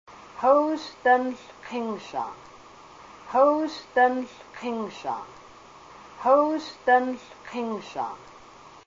The following are a collection of phrases recorded with native speakers of Haida during a trip to Ketchikan and Hydaburg, Alaska, in March, 2003.
a native speaker of the Kasaan dialect of Alaskan Haida.